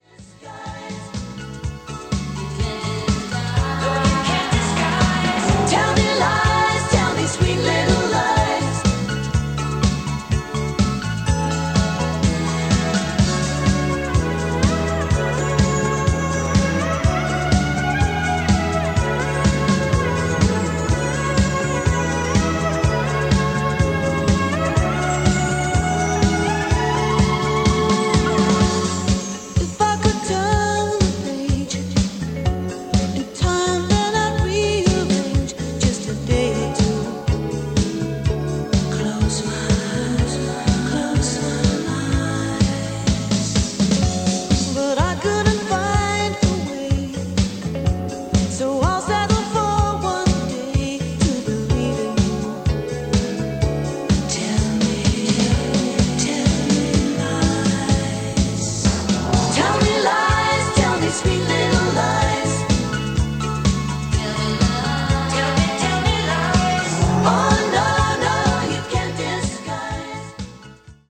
Below is a test recording made by the KX-390 with the AutoTune function used for optimal recording:
Yamaha-KX-390-Test-Recording.mp3